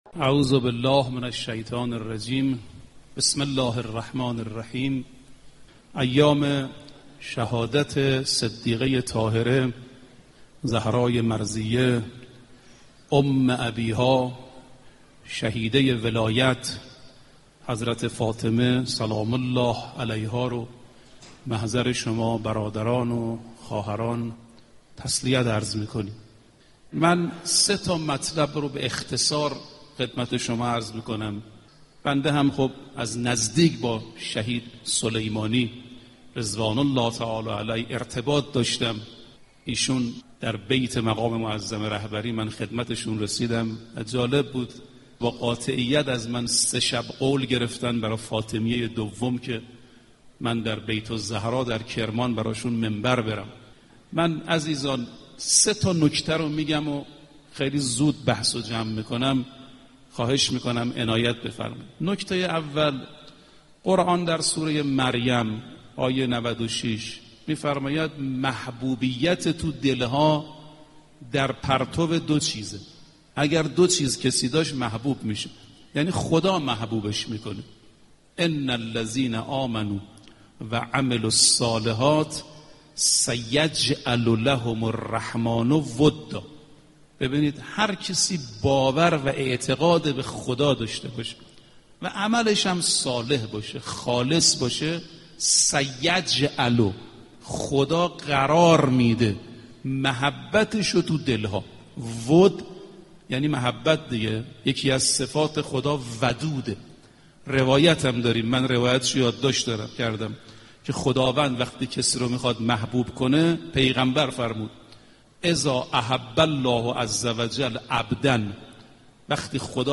سخنرانی حجت الاسلام رفیعی با موضوع ویژگی‌های شهید سلیمانی